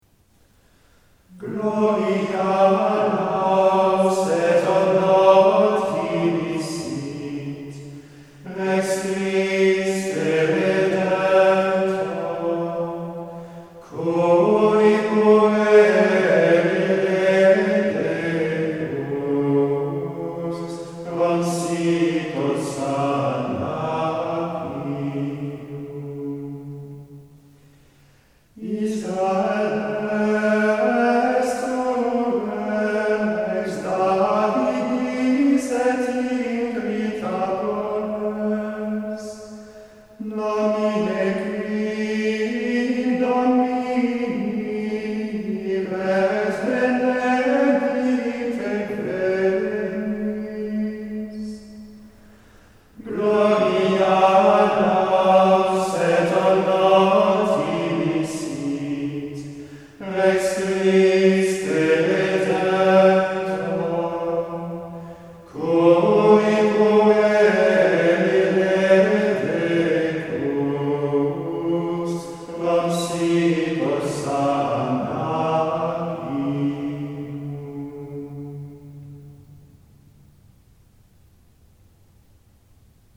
Listen to professional recordings: